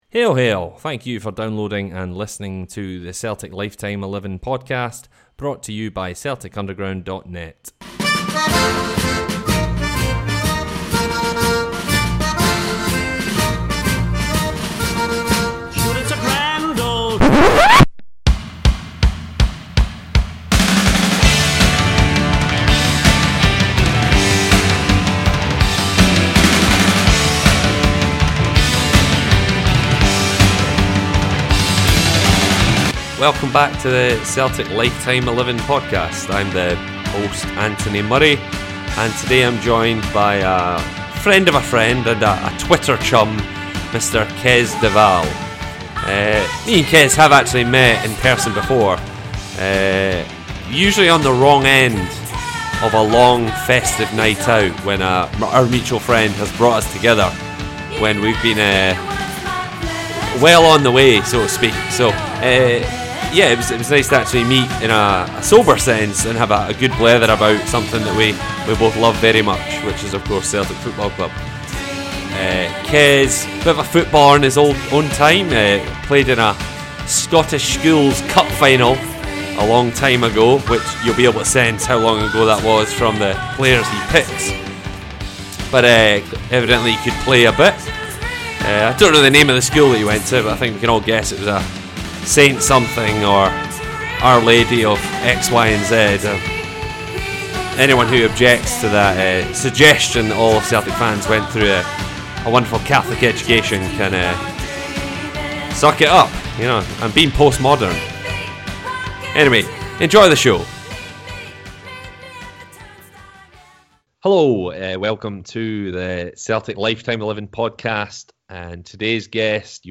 two fans shooting the breeze discussing their Celtic XI